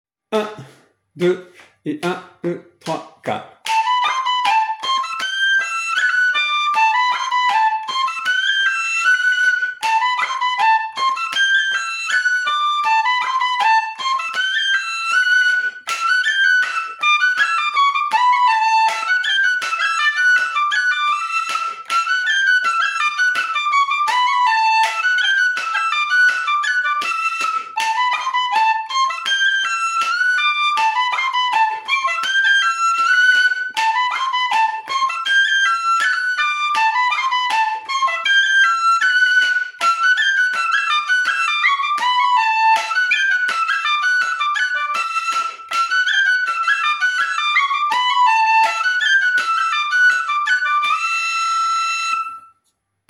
Violons
les-boyaux-thème-_violons-et-flûtes__1.mp3